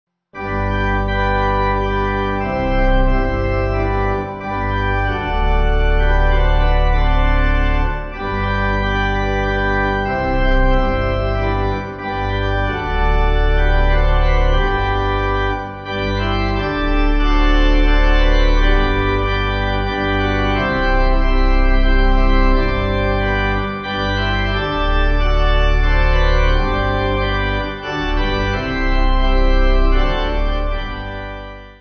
Organ
(CM)   4/G